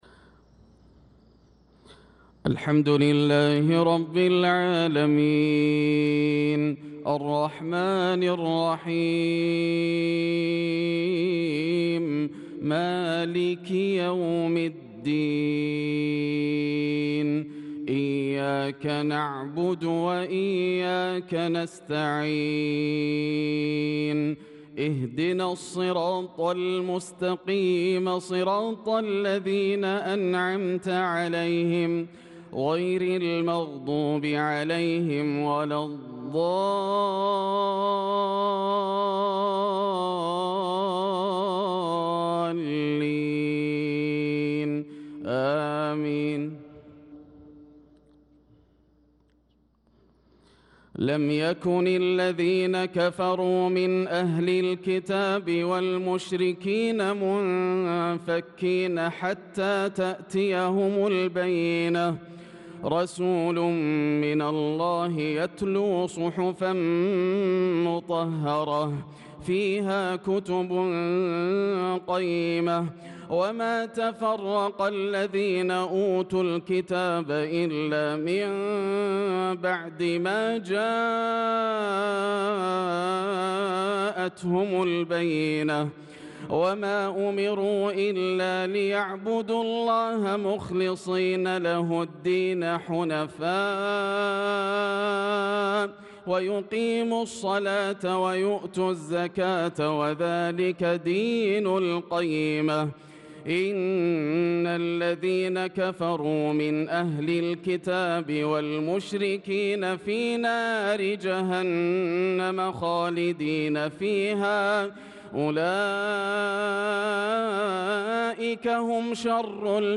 صلاة المغرب للقارئ ياسر الدوسري 14 ذو القعدة 1445 هـ
تِلَاوَات الْحَرَمَيْن .